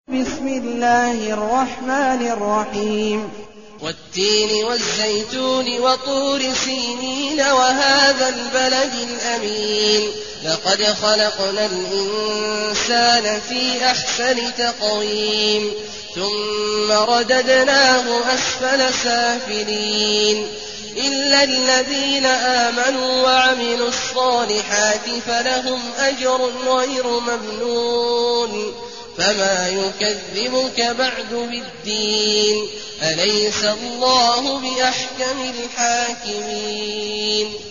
المكان: المسجد النبوي الشيخ: فضيلة الشيخ عبدالله الجهني فضيلة الشيخ عبدالله الجهني التين